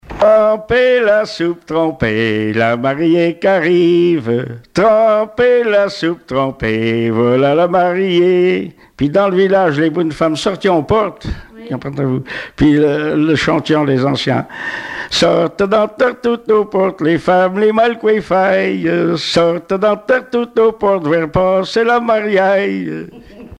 Témoignages et chansons traditionnelles et populaires
Pièce musicale inédite